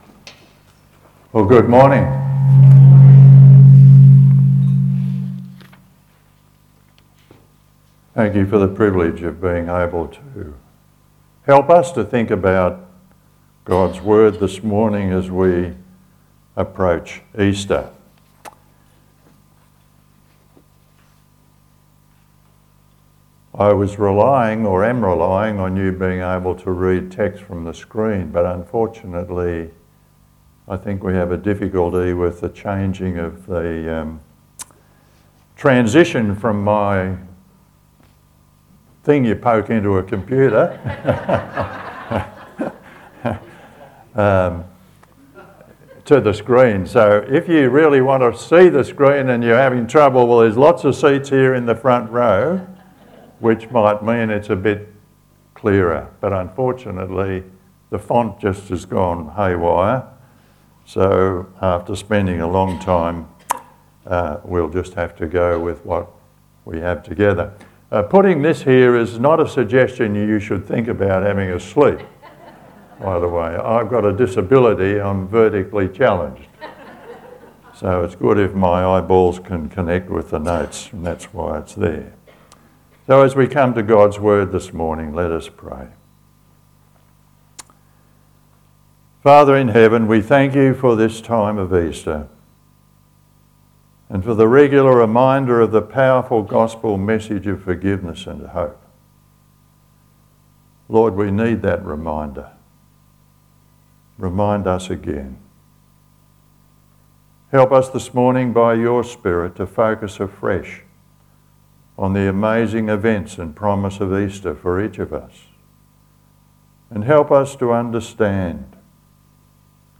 Lilydale Baptist Church 10 am Service: Sermon